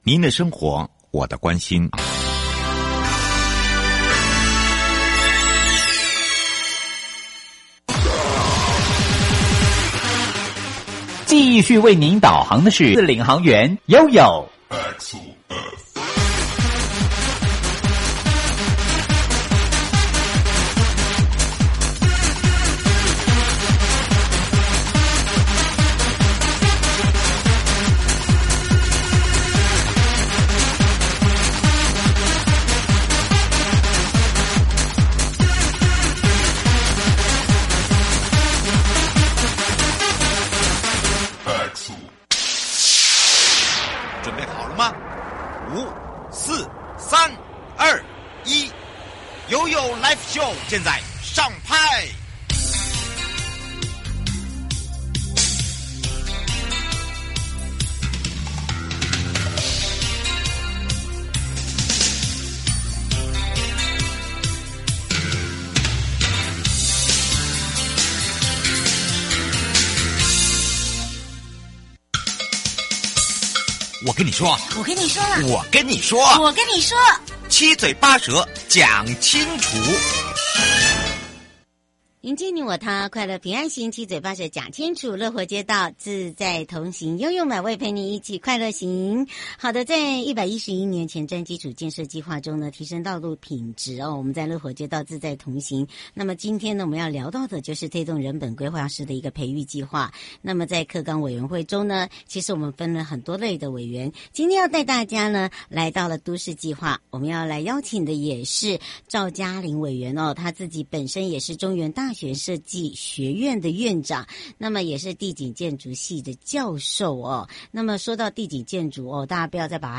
受訪者： 營建你我他 快樂平安行~七嘴八舌講清楚~樂活街道自在同行!(二) 公共通行空間關乎社區的生活，為通勤通學廊道、校前廣場、人行街道、公園綠地、街廓轉角等，影響社區每日生活與社交的空間。